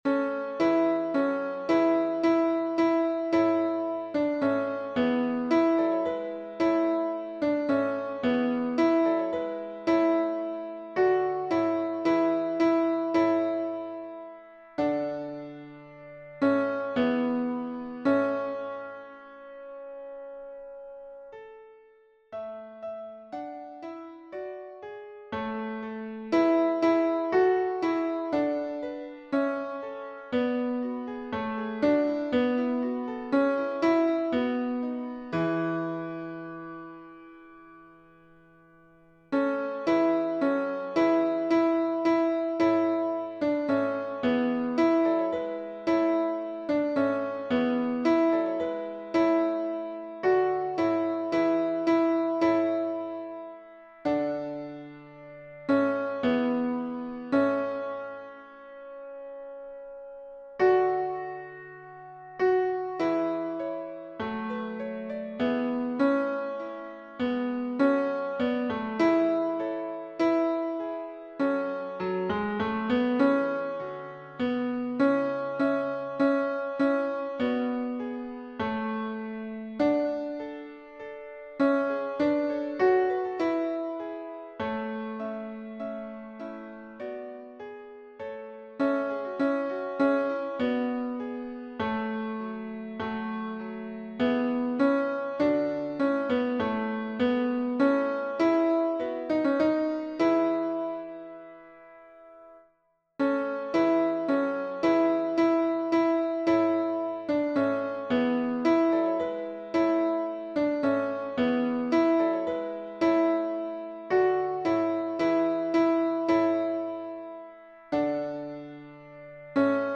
Para aprender la melodía podéis utilizar estos enlaces instrumentales en formato MP3:
Regina caeli T MIDIDescarga
regina-caeli-t-midi.mp3